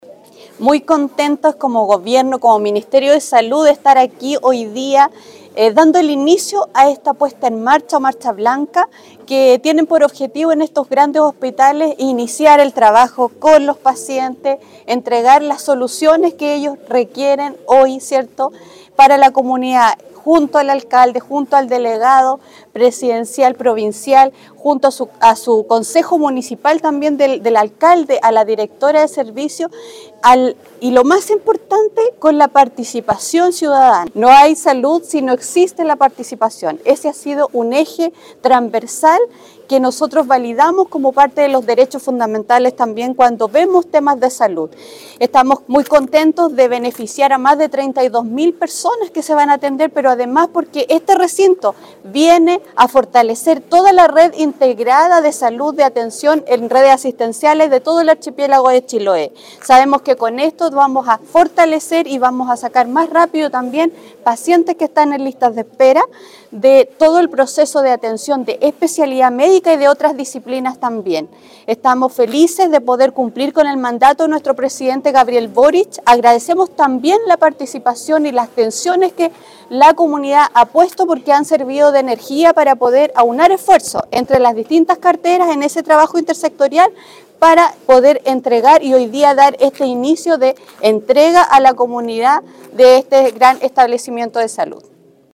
De igual manera, la Seremi de Salud de Los Lagos, Karin Solís, destacó que esta apertura beneficiará a más de 32 mil personas y que viene a fortalecer la red pública de salud de todo el Archipiélago de Chiloé: